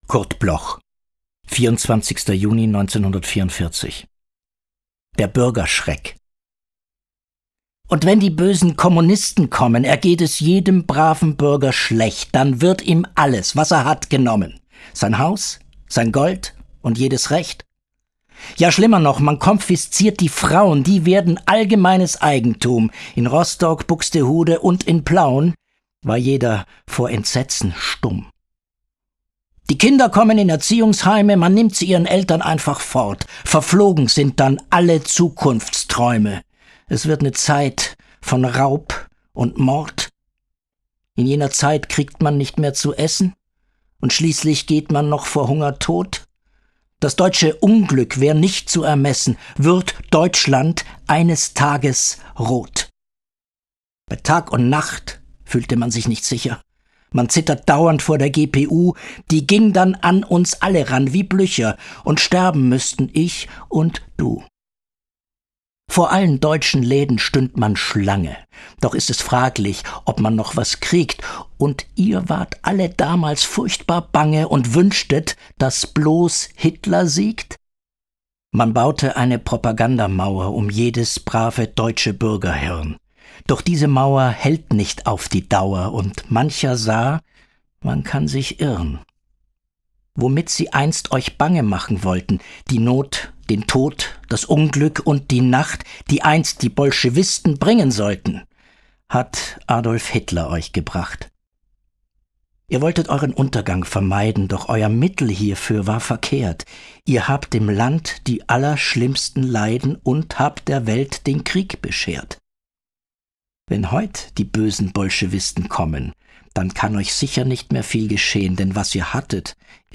Recording: Residenztheater München · Editing: Kristen & Schmidt, Wiesbaden